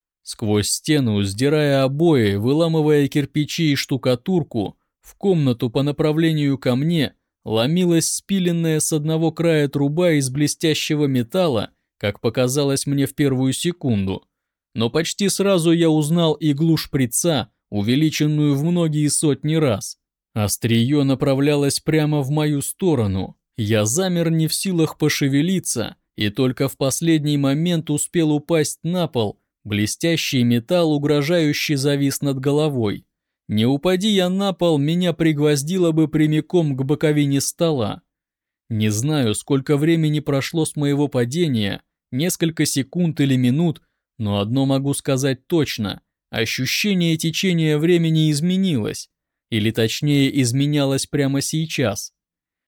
Муж, Аудиокнига/Средний
Focusrite 2i2 2nd gen., Audio-Technica AT2035